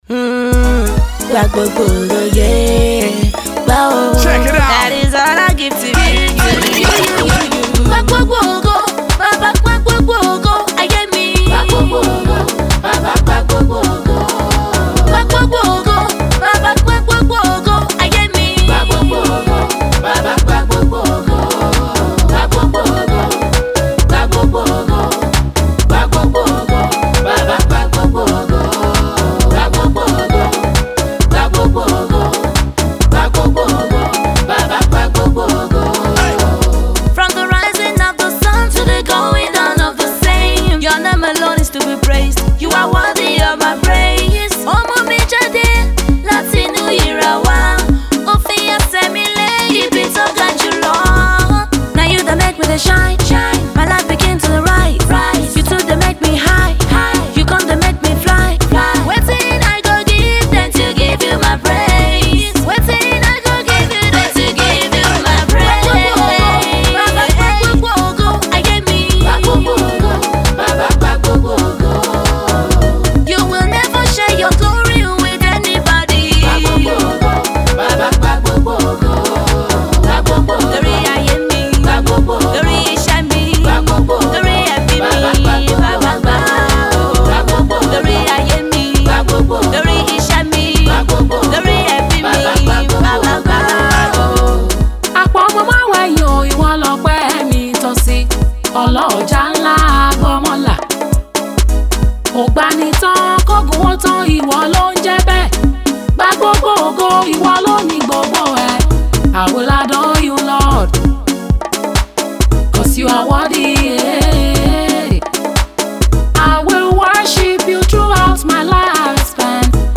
American-based gospel minister
high praise single